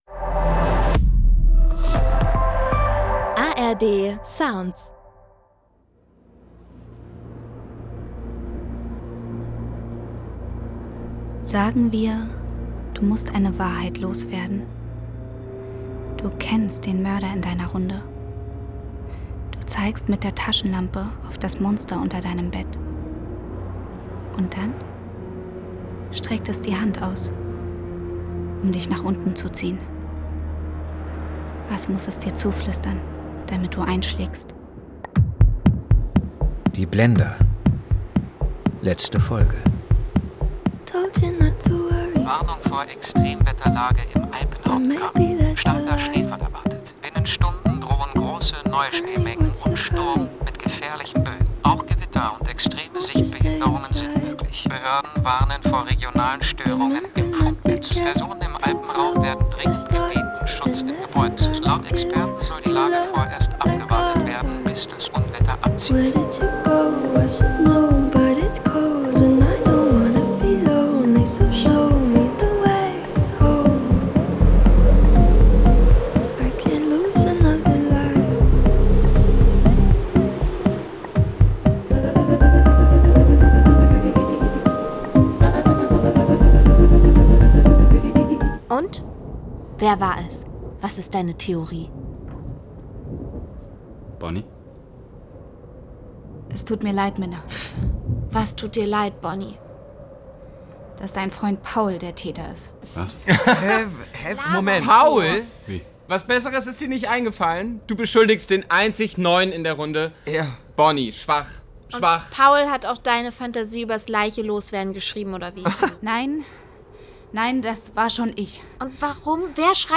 Die Blender: Bonnie (8/8) – Das große Finale ~ Die Blender – Crime-Hörspiel-Serie Podcast